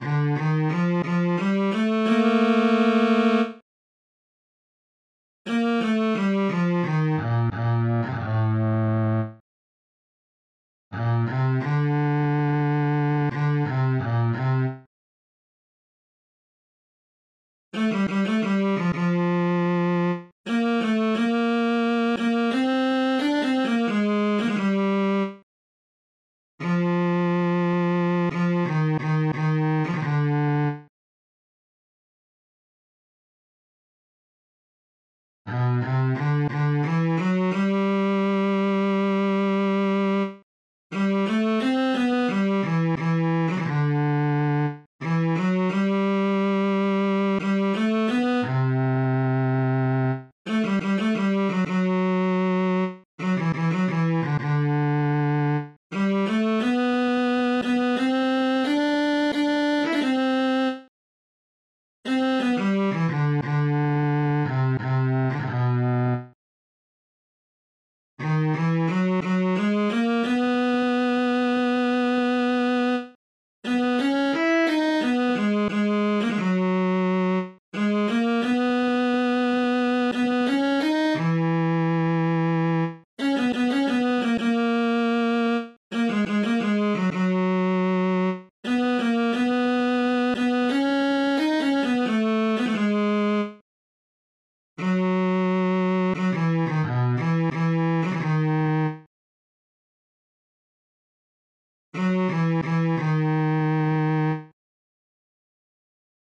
婚禮獻詩檔案
生之頌(男生鋼琴版):
beauty_altos.mp3